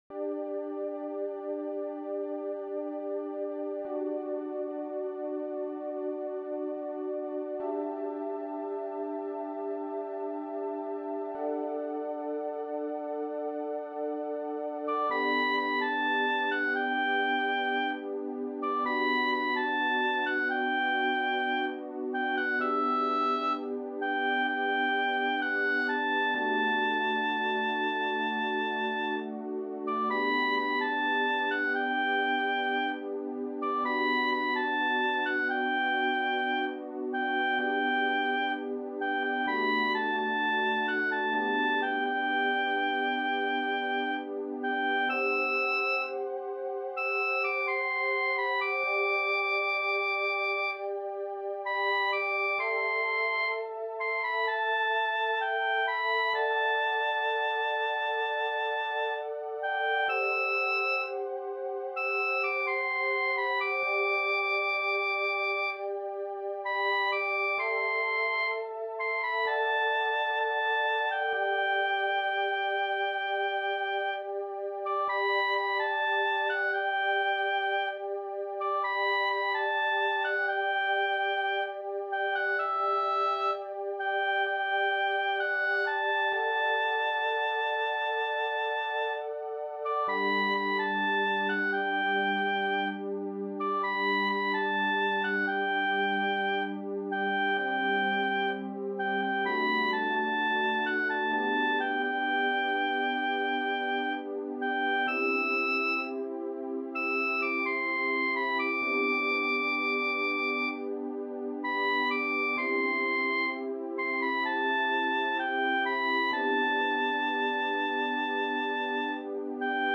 G(원키)